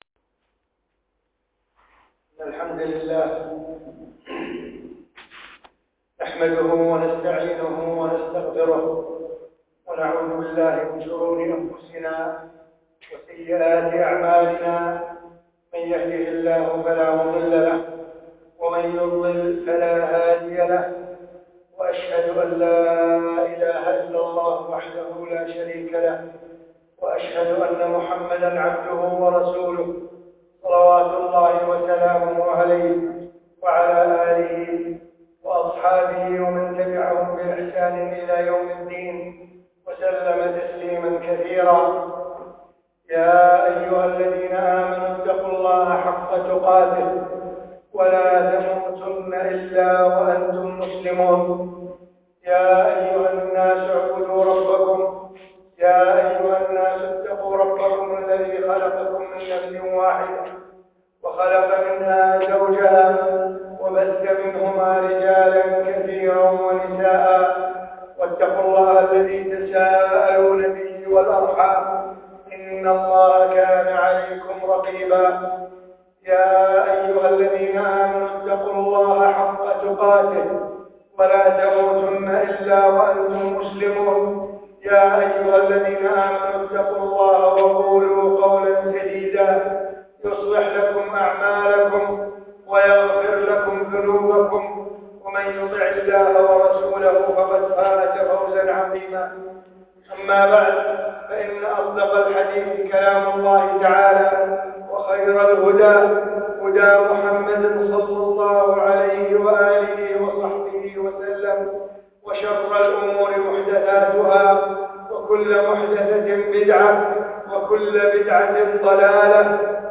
خطبة جمعة ٢ شعبان١ ٤ ٣ ٠